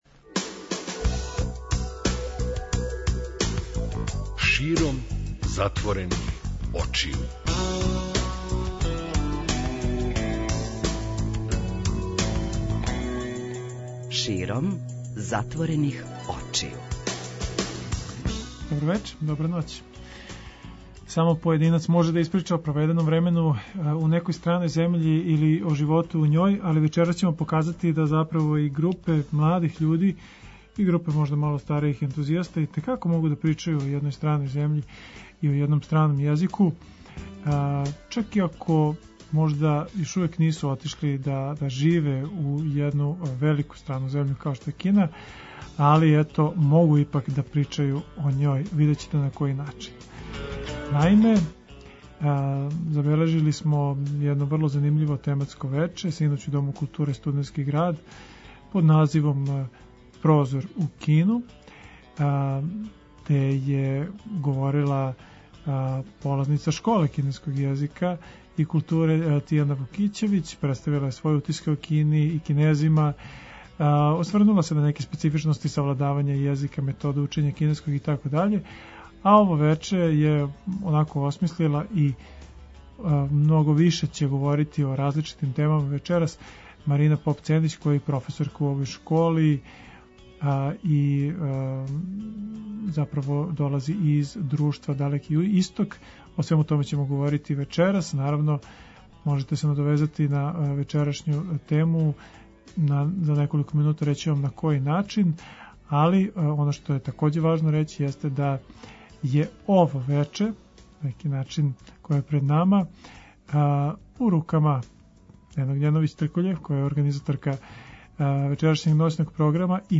Забележено је занимљиво тематско вече, синоћ, у Дому културе Студентски град под називом „Прозор у Кину”.